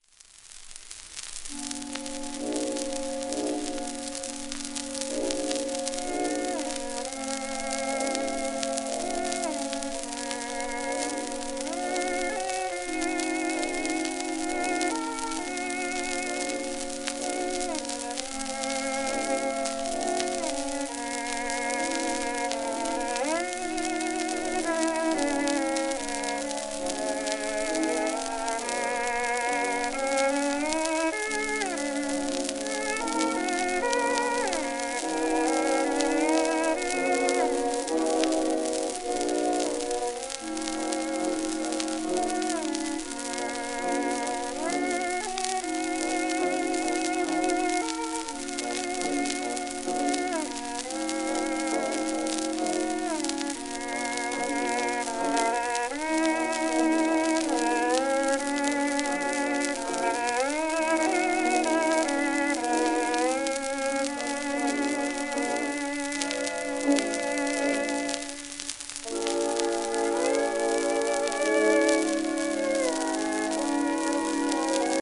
w/オーケストラ
旧 旧吹込みの略、電気録音以前の機械式録音盤（ラッパ吹込み）